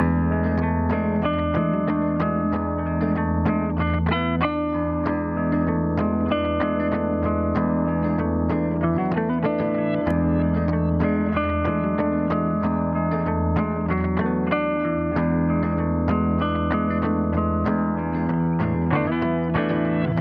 95 Bpm 韵律吉他
和弦：CMa7 FMa7
标签： 95 bpm Jazz Loops Guitar Electric Loops 3.40 MB wav Key : C
声道立体声